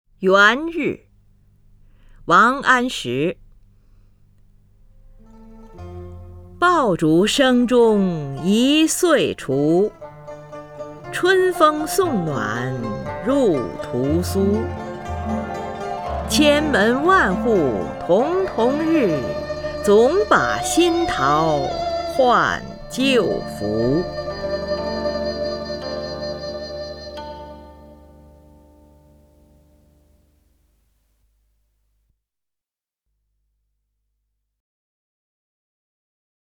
林如朗诵：《元日》(（北宋）王安石) （北宋）王安石 名家朗诵欣赏林如 语文PLUS